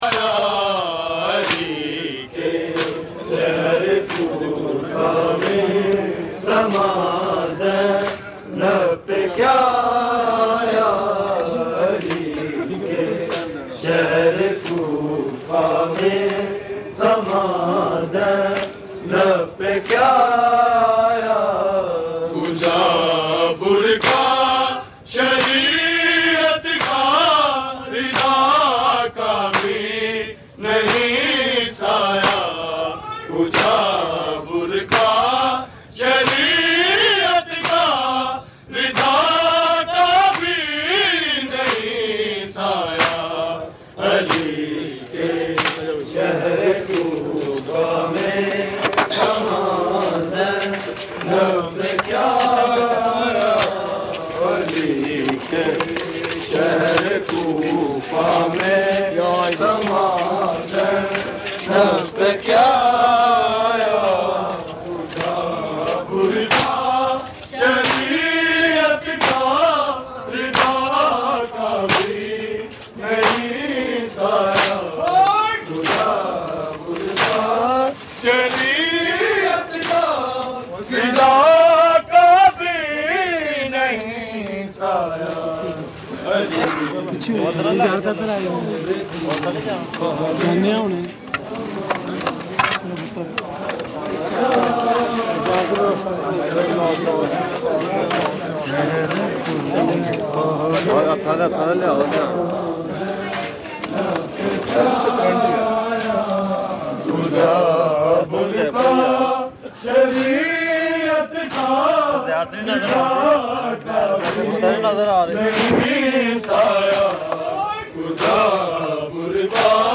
Recording Type: Live
Location: Al Abbas Imam Bargah Chuna Mandi Lahore